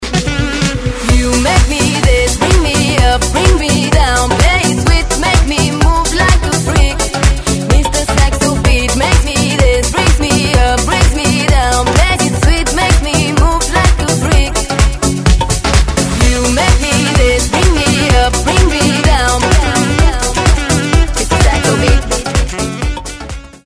с классной танцевальной музыкой
Танцевальные рингтоны
Саксофон , Зажигательные , Евродэнс
Dance pop